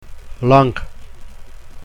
Nasals